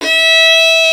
STR FIDDLE07.wav